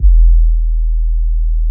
DB - Kick (17).wav